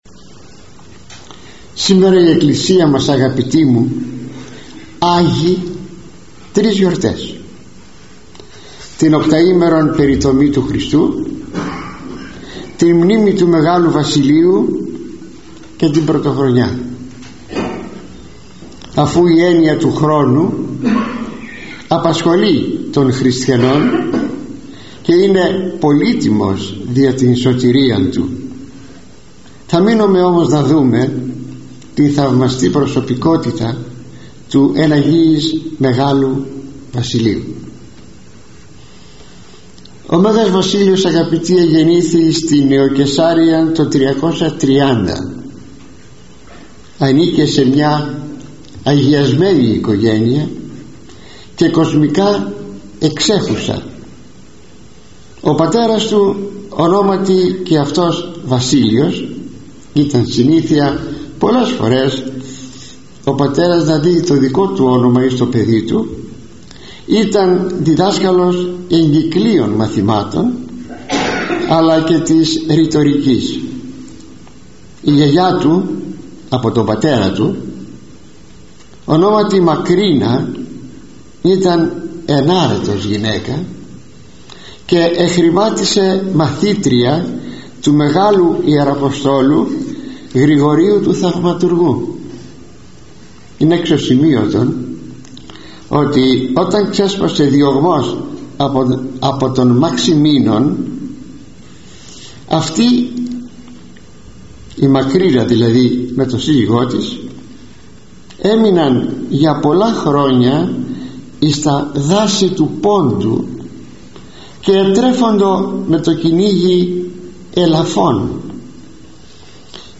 [εκφωνήθηκε στην Ιερά Μονή Κομνηνείου Λαρίσης στις 1-1-2002]